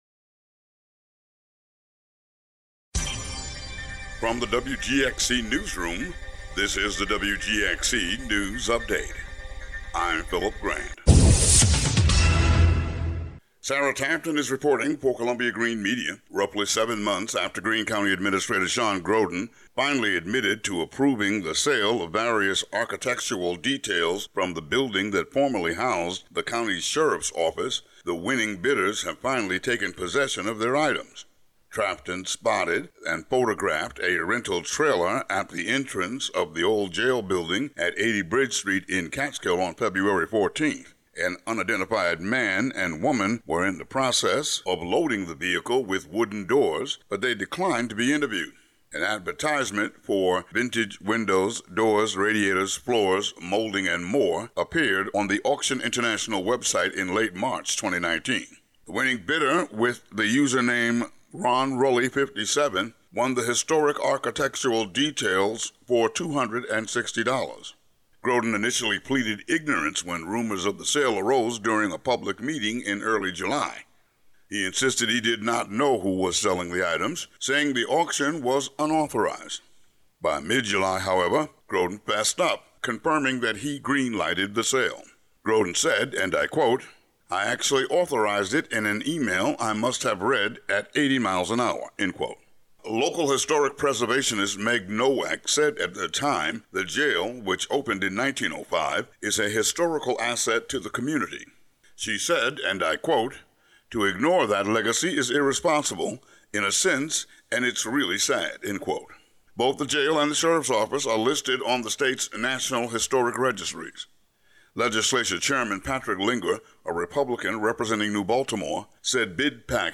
Today's local news update.